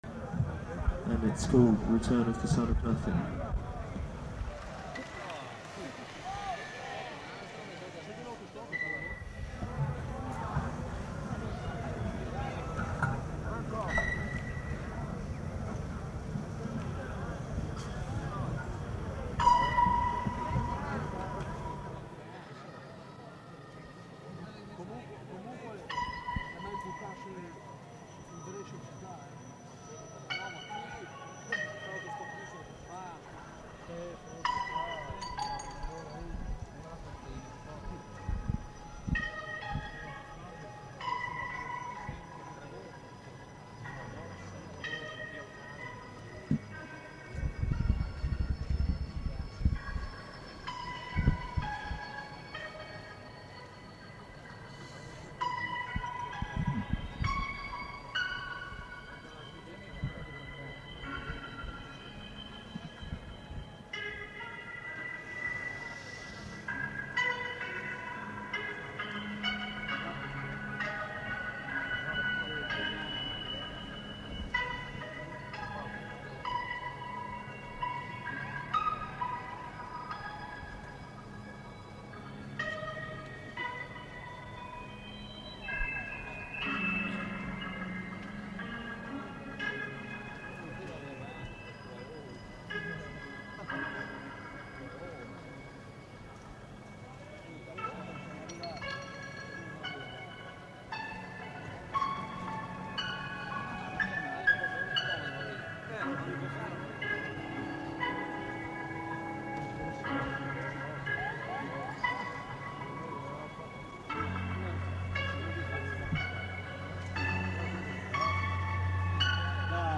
از نوارهای مخاطب با ریمسترینگ واضح ضبط شده